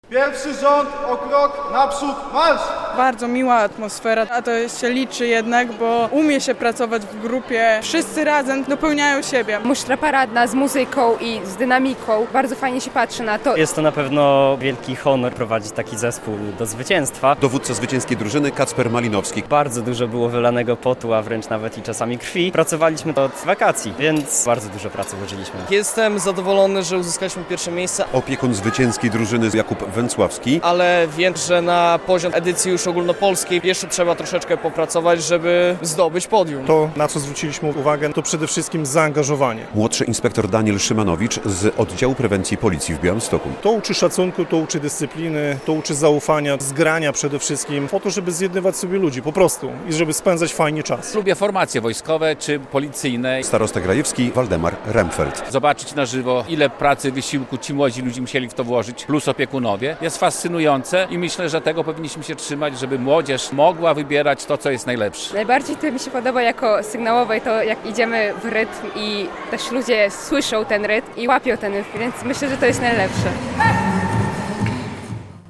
Konkurs musztr - relacja